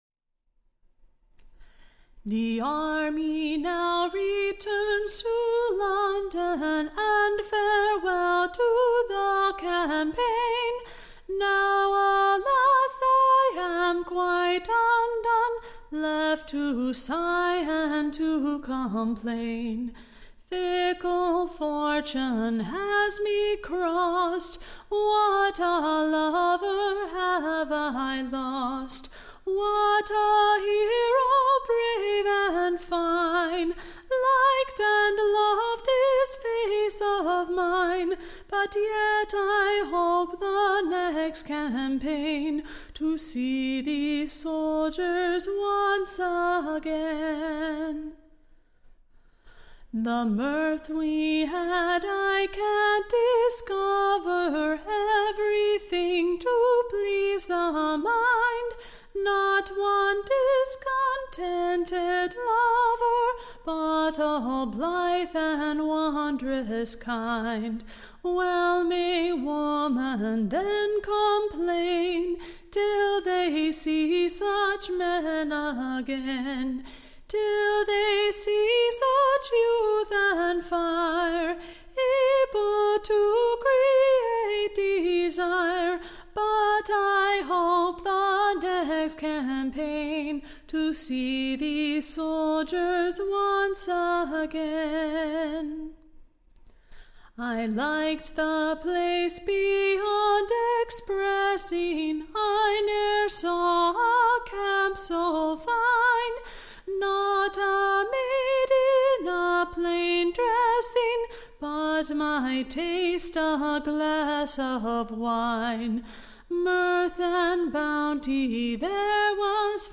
EBBA 21354 - UCSB English Broadside Ballad Archive
Recording Information Ballad Title The Westminster Madams Lamentation / For the breaking up of the CAMPAIGN at Hounslow-Heath, and the loss of their Pleasure / they used to receive there.
Tune Imprint To the Tune of, O Mother!